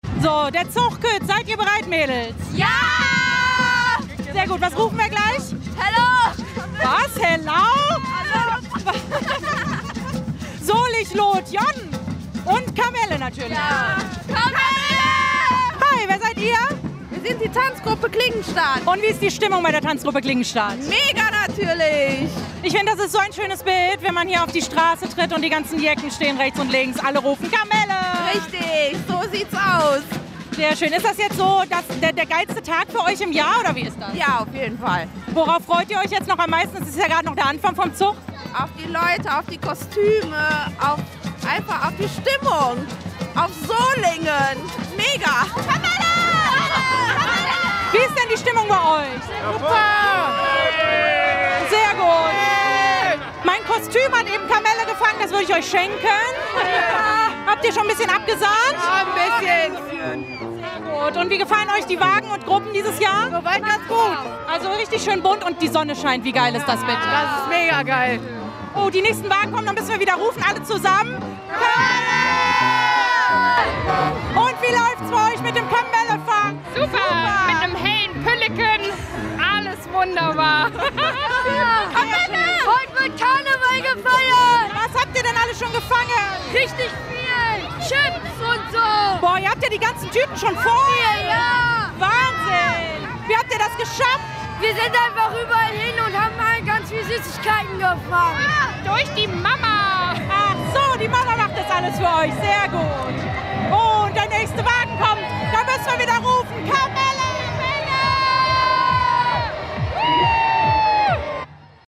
Radio RSGRosenmontag 2024 in Solingen Umfrage